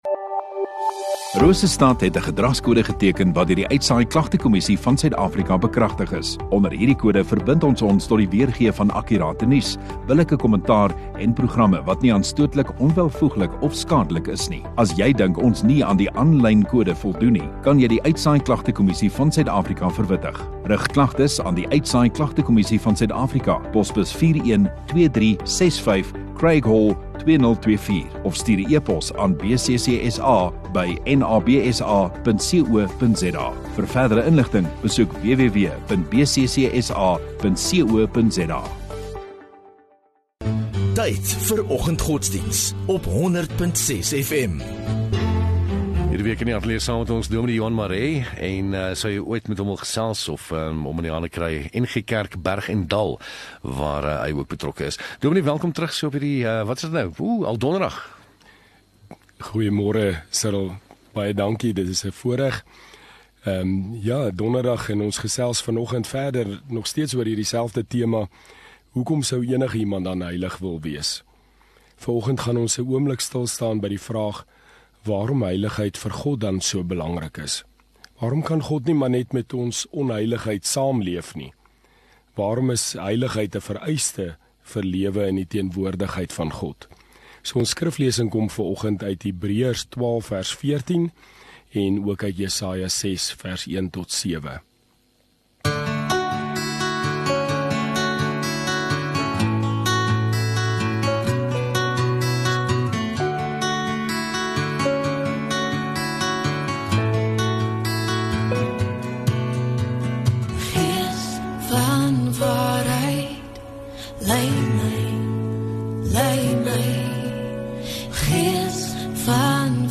11 Jul Donderdag Oggenddiens